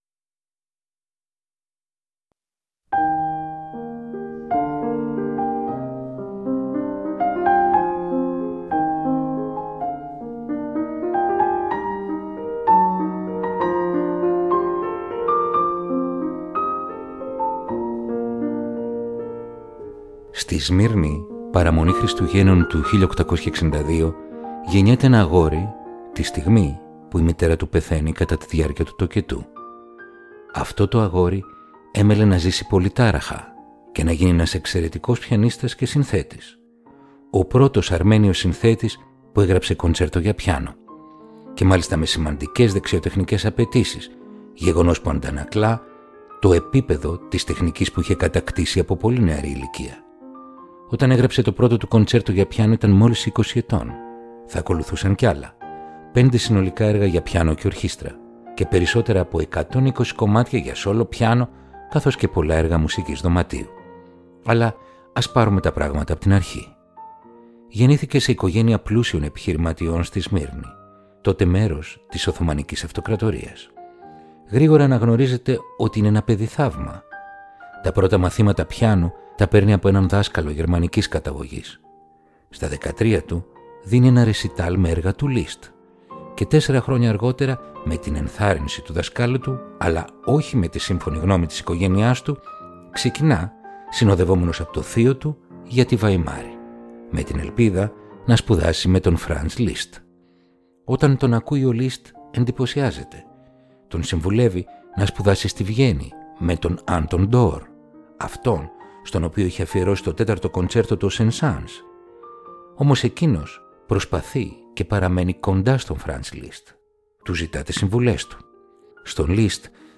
Ρομαντικά κοντσέρτα για πιάνο – Επεισόδιο 20ο